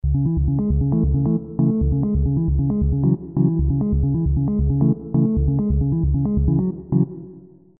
Organ: D50 soft
organ2.mp3